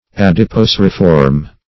Search Result for " adipoceriform" : The Collaborative International Dictionary of English v.0.48: Adipoceriform \Ad`i*po*cer"i*form\, a. [Adipocere + -form.] Having the form or appearance of adipocere; as, an adipoceriform tumor.